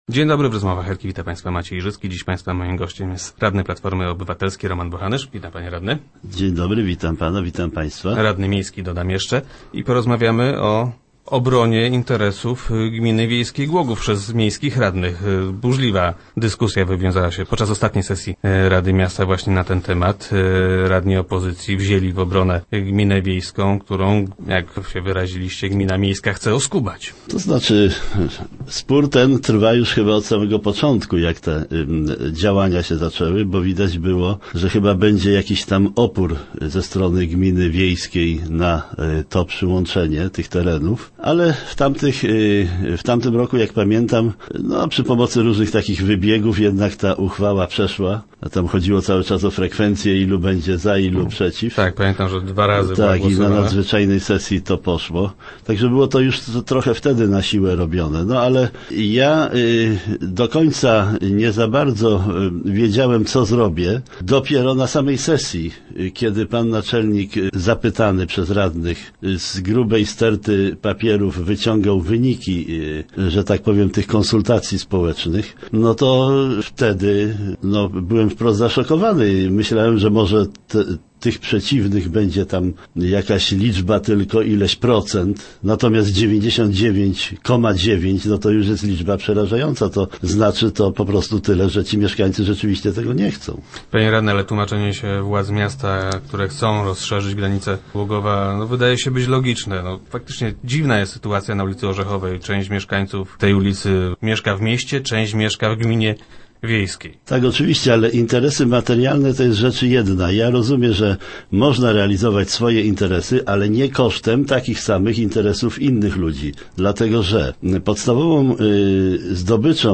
- Mieszkańcy tej gminy po prostu tego nie chcą - mówił Roman Bochanysz, radny Platformy Obywatelskiej, który był gościem poniedziałkowych Rozmów Elki.